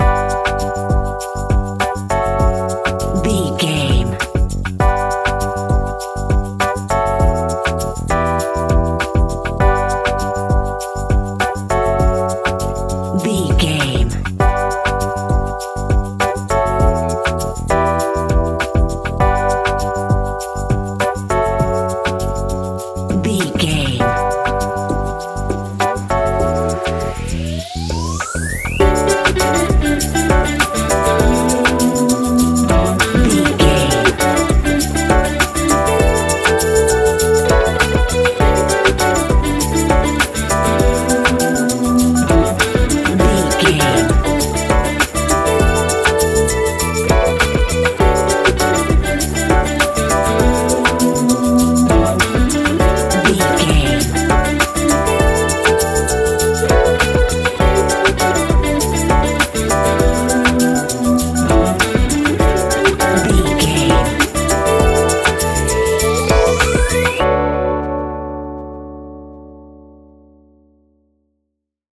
Ionian/Major
D♭
chilled
laid back
Lounge
sparse
new age
chilled electronica
ambient
atmospheric